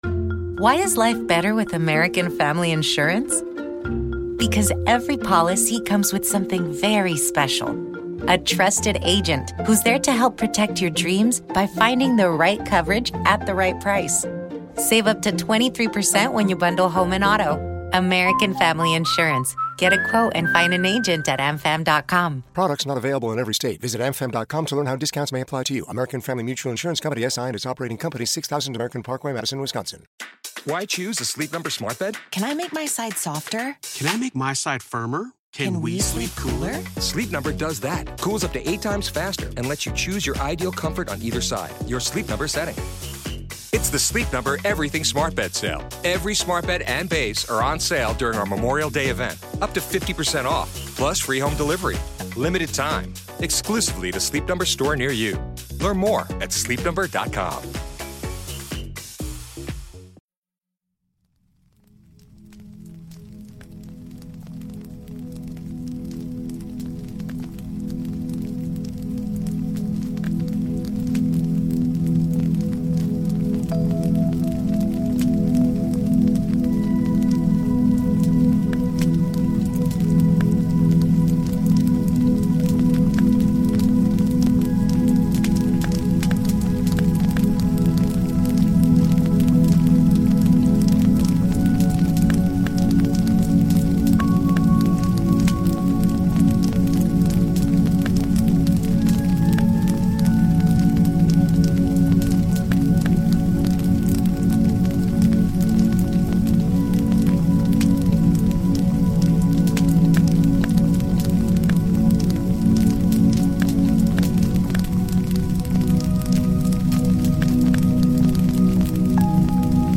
Here is a wonderful dreamland to help sleep and relax.